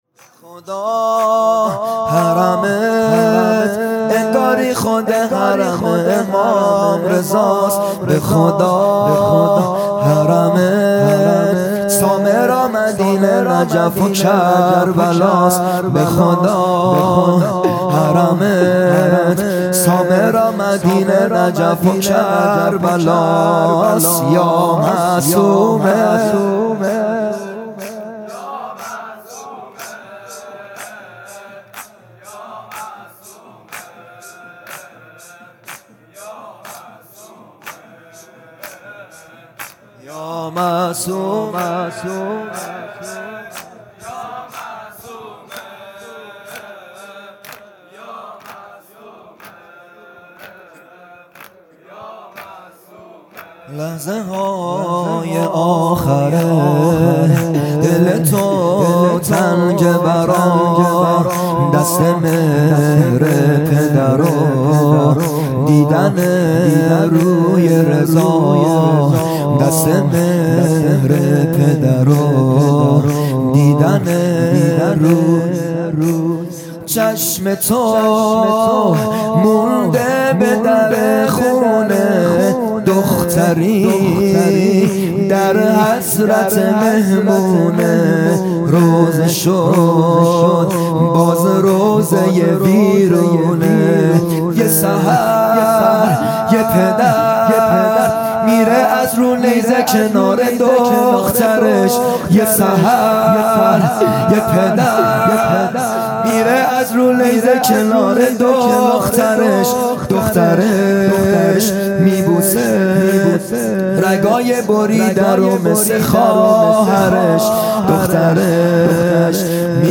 زمینه | به خدا حرمت انگاری خود حرم امام رضاست
جلسۀ هفتگی | به مناسبت شهادت حضرت معصومه(س) | ‍به یاد شهید آیت الله حسن مدرس | 14 آذر 1398